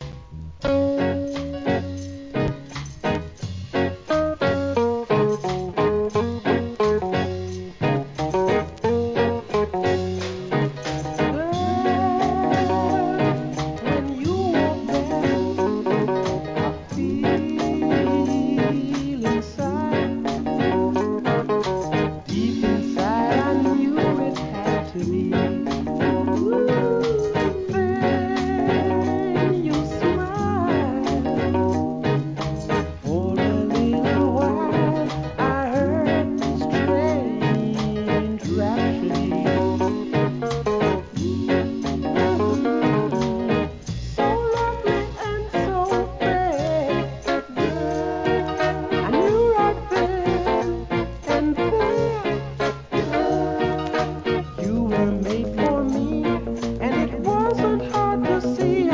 REGGAE
心地よいRHYTHMにマッチする素晴らしいヴォーカルで聴かせるROCKSTEADY & SKA名盤!!